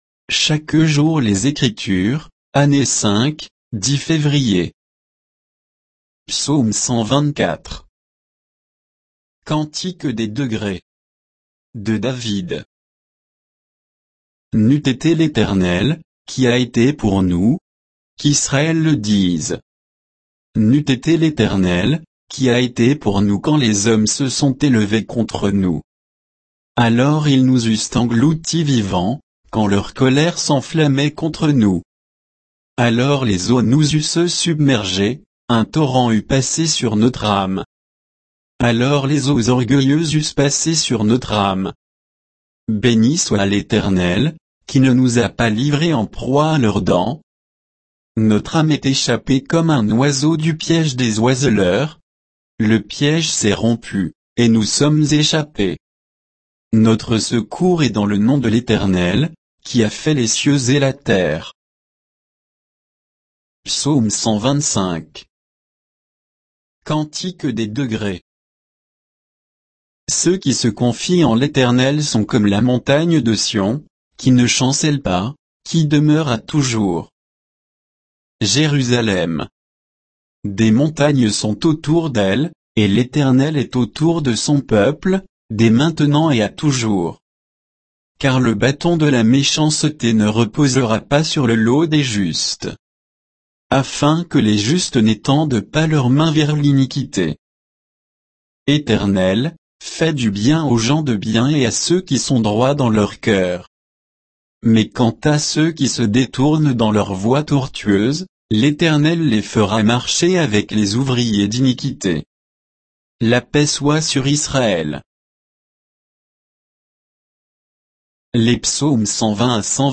Méditation quoditienne de Chaque jour les Écritures sur Psaumes 124 et 125